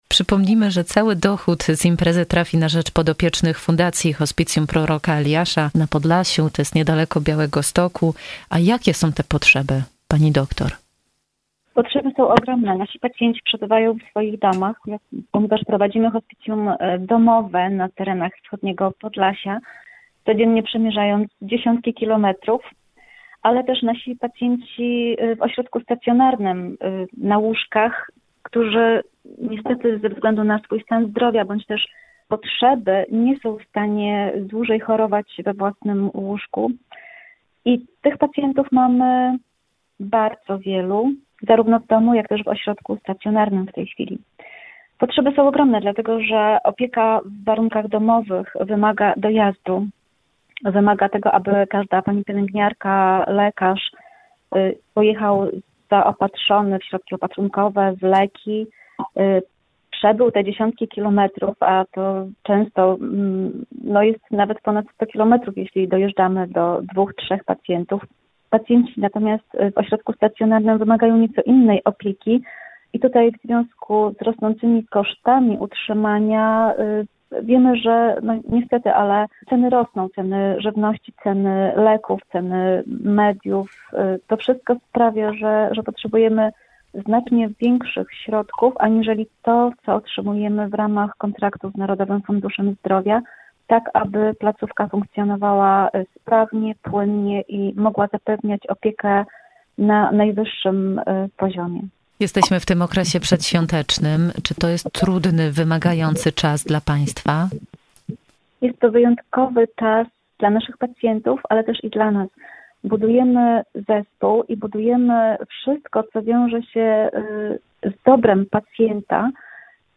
Więcej w rozmowie z naszymi gośćmi
02_Hospicjum-Proroka-Eliasza_rozmowa.mp3